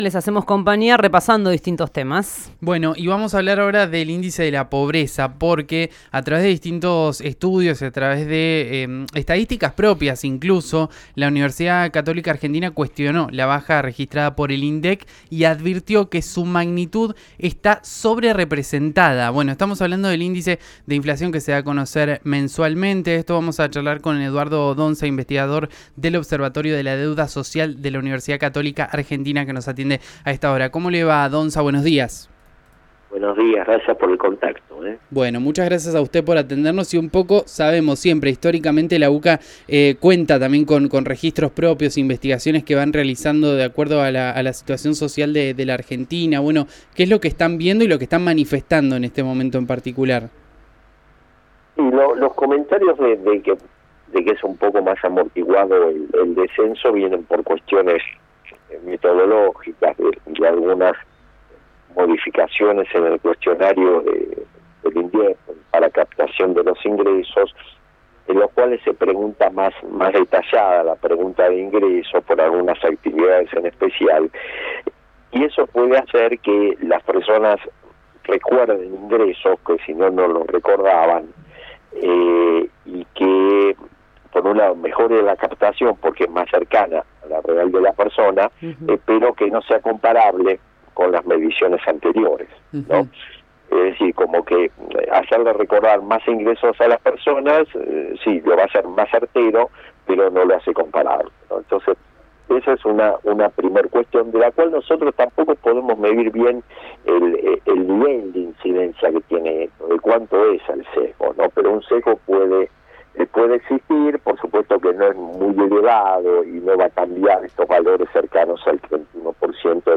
RÍO NEGRO Radio